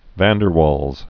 (văn dər wôlz, wälz), Johannes Diderik 1837-1923.